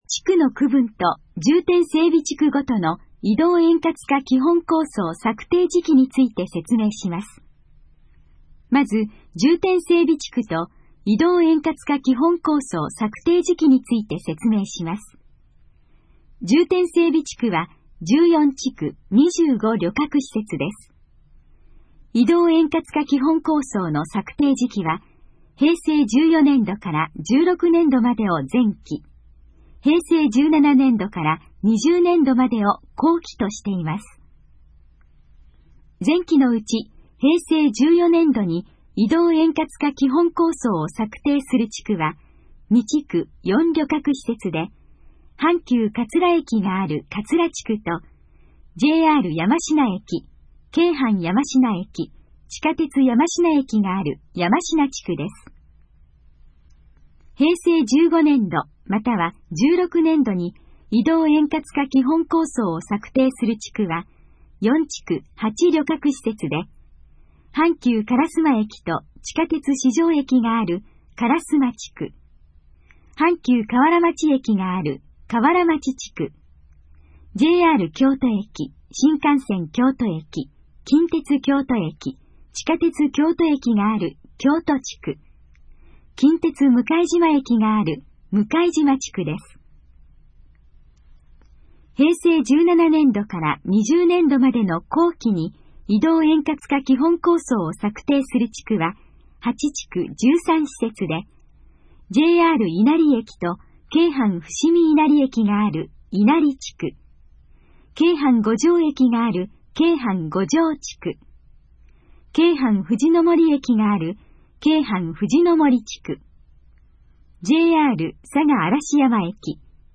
このページの要約を音声で読み上げます。
ナレーション再生 約573KB